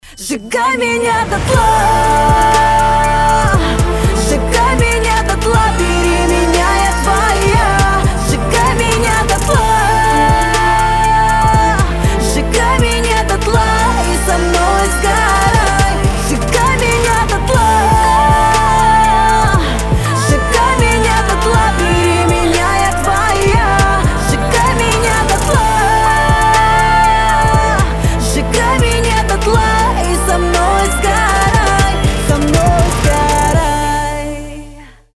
• Качество: 320, Stereo
поп
громкие
эмоциональные